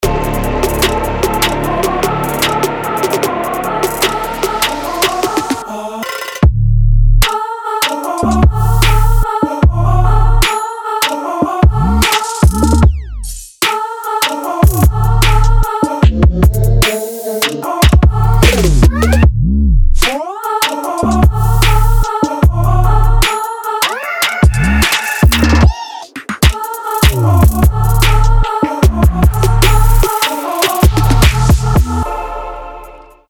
атмосферные
без слов
Trap
Bass